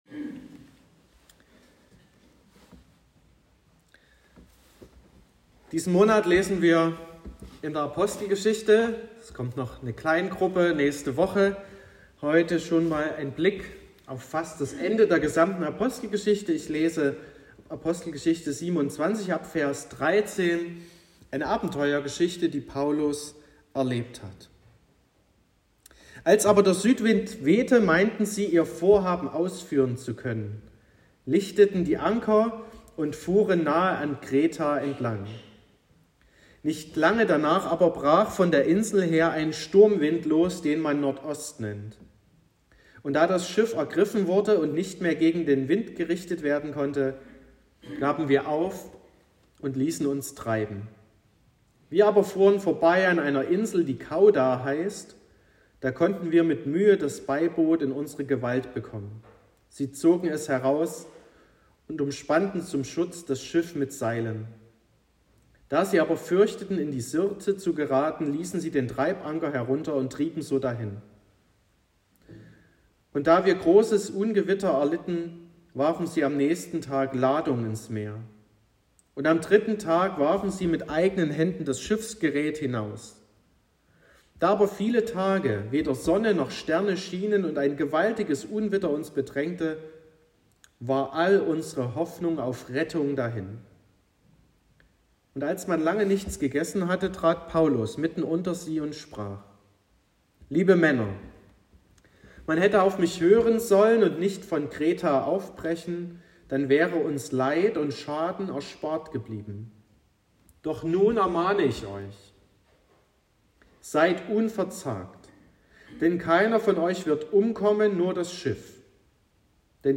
24.09.2023 – Gottesdienst zur Bibelwoche
Predigt und Aufzeichnungen